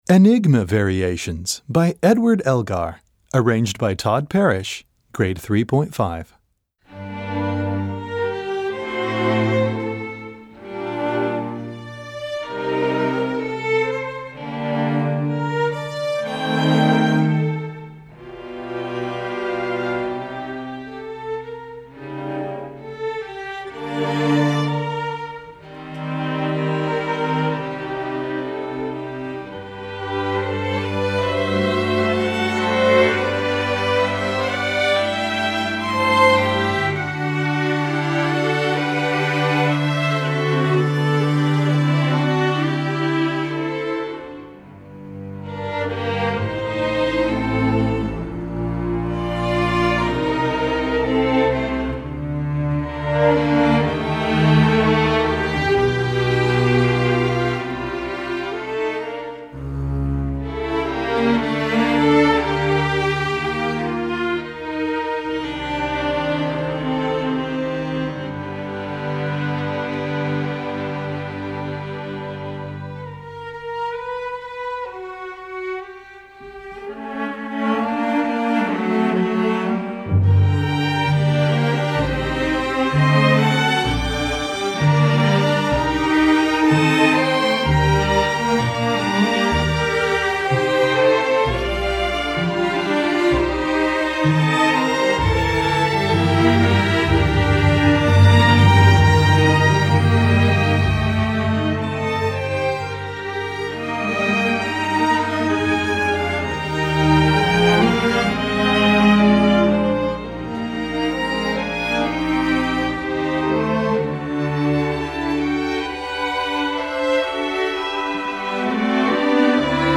Gattung: Streichorchester
Besetzung: Streichorchester
String orchestras